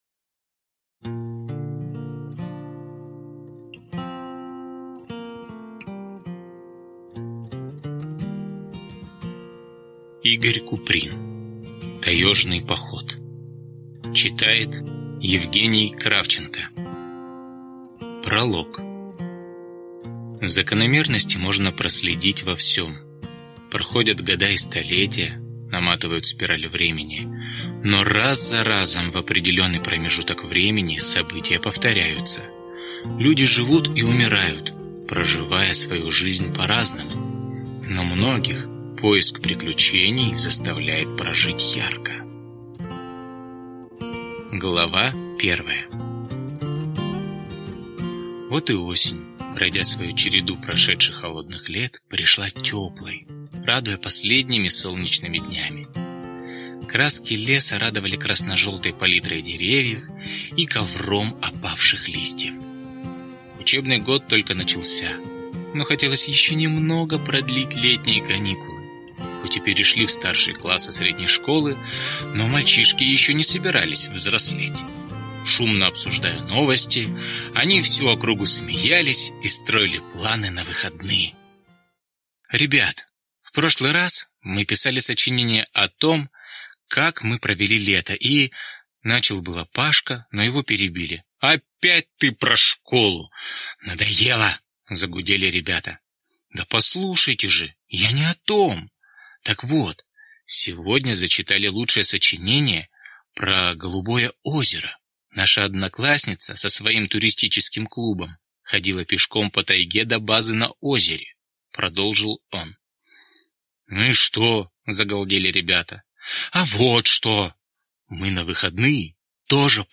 Аудиокнига Таёжный поход | Библиотека аудиокниг